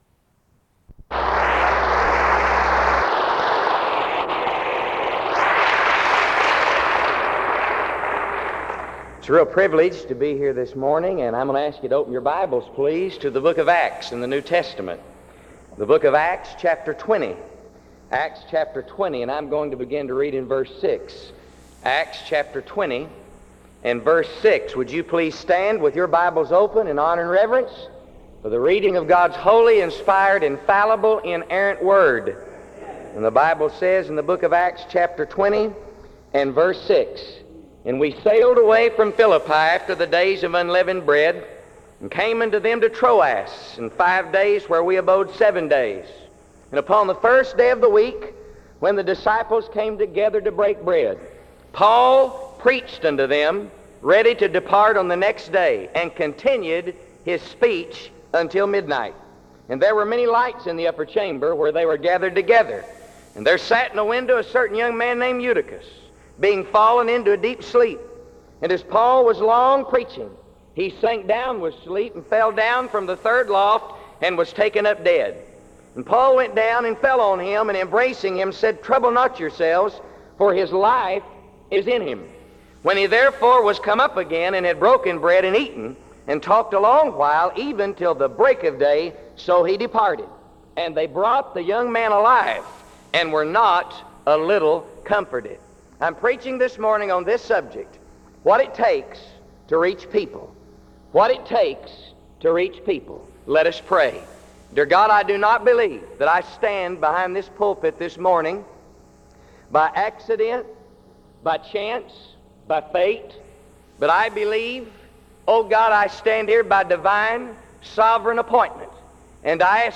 Location Wake Forest (N.C.)
SEBTS Chapel and Special Event Recordings - 2000s